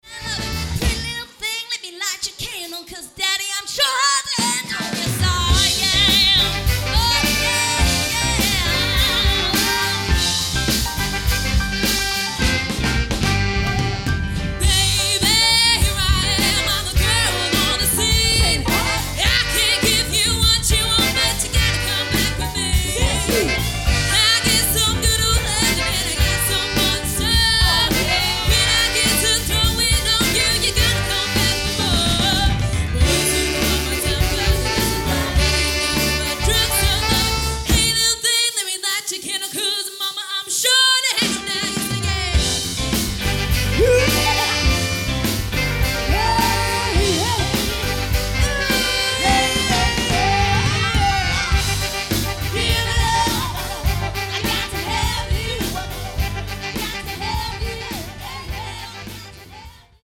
A live album, recorded at Granvilles Bar & Brasserie, Stone.
Hard working, saviours of soul.